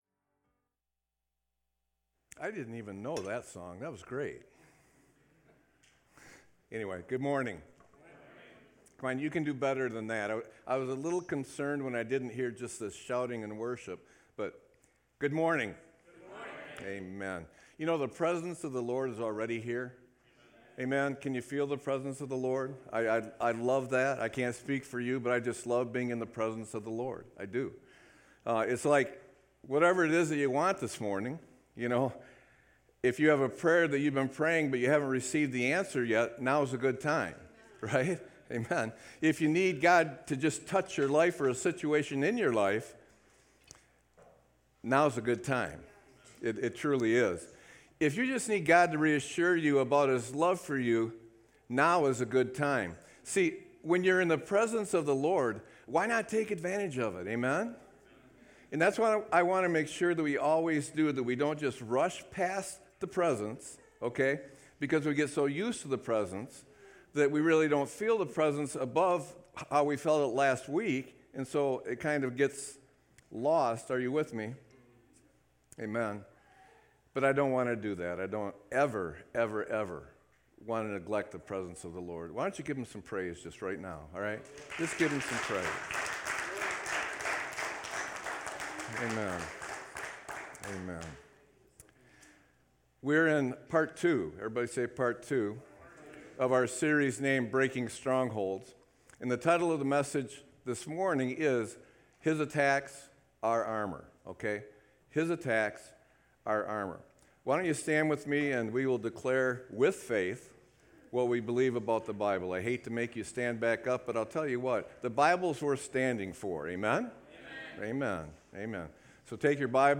Sermon-3-17-24.mp3